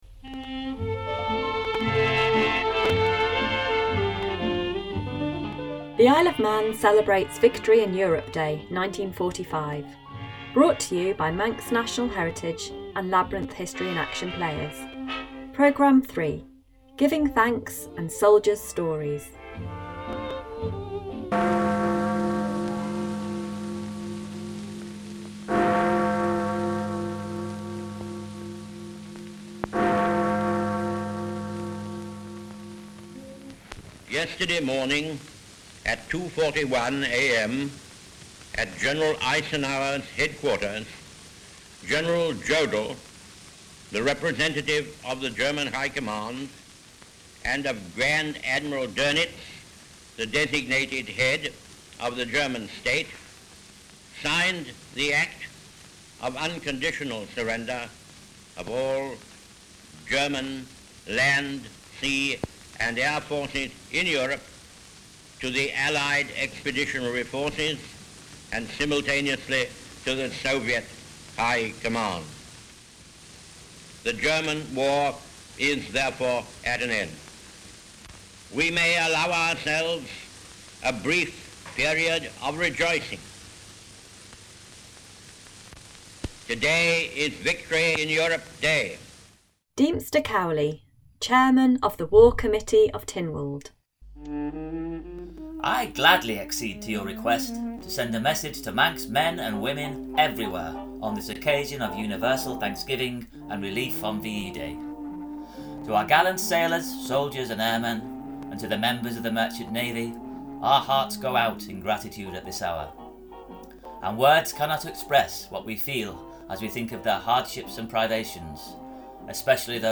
As we mark the 80th anniversary of VE Day, Manx National Heritage Labyrinth History in Action Players bring you some of the sounds and reports of VE Day in the IOM.